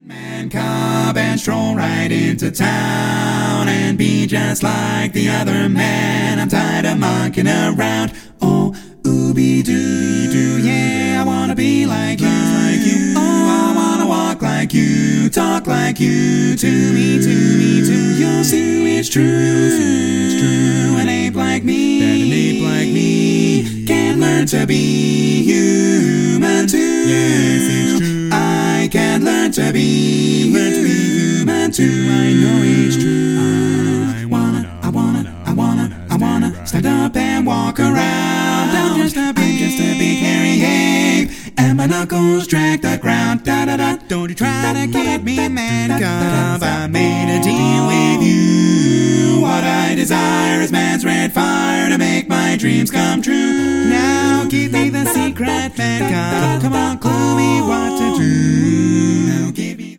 Full mix only
Category: Male